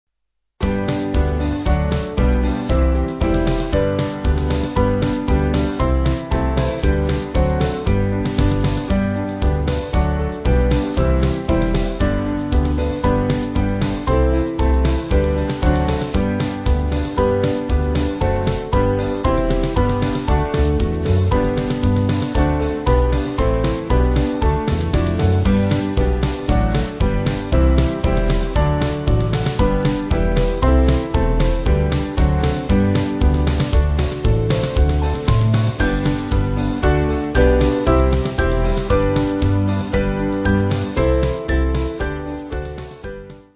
6.6.6.6.D
Small Band